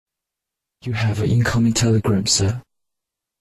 钢铁侠贾维斯音效_人物音效音效配乐_免费素材下载_提案神器
钢铁侠贾维斯音效免费音频素材下载